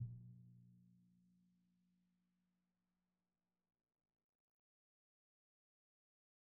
Percussion / Timpani
Timpani2_Hit_v1_rr1_Sum.wav